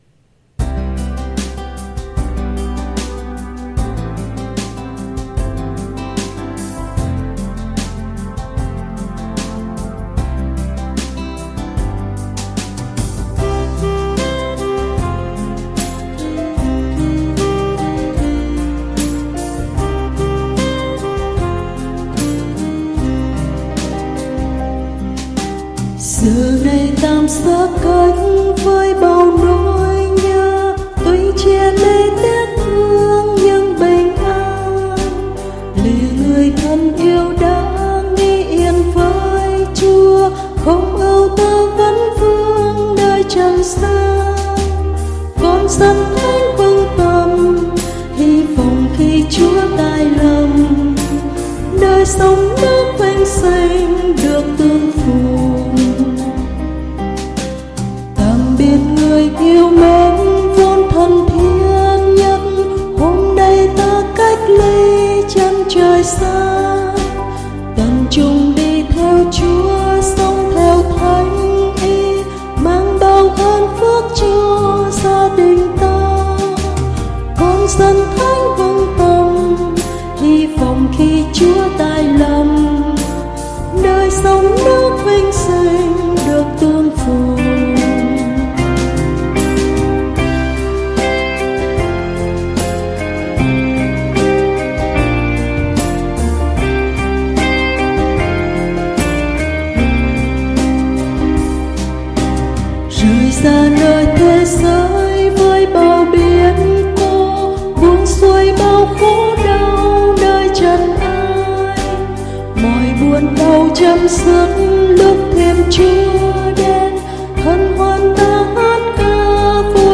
Audio Nhạc Thánh